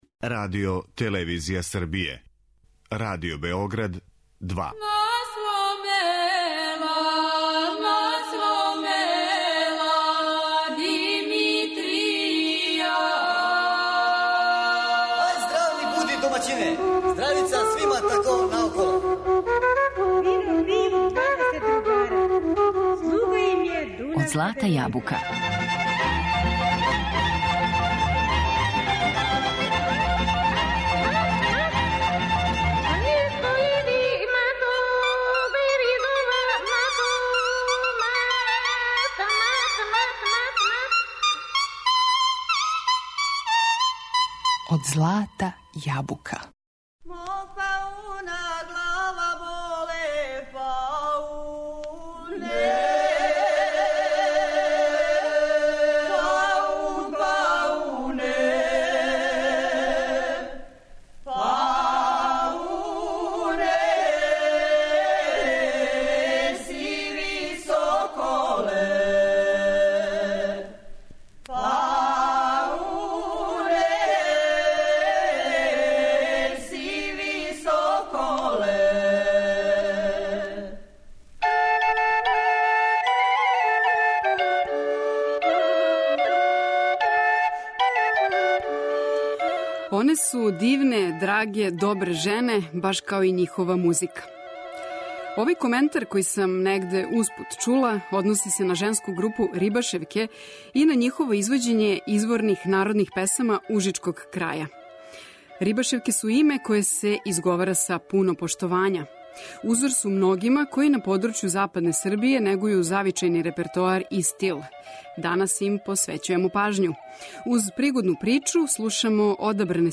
Рибашевке - женска певачка група из западне Србије.
Група носи име по селу Рибашевина (Ужице). Ове певачице из народа су узор многима који на подручју западне Србије негују завичајни репертоар и стил.